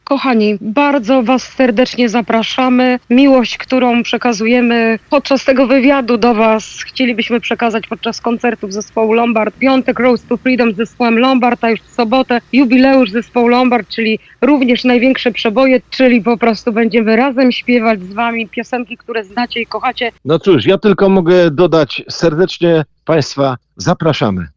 Na antenie Radia Deon Chicago lider grupy, kompozytor, instrumentalista i wokalista – Grzegorz Stróżniak, oraz Marta Cugier – wokalistka pisząca teksty oraz menager, zapowiadają swoje przybycie do Wietrznego Miasta.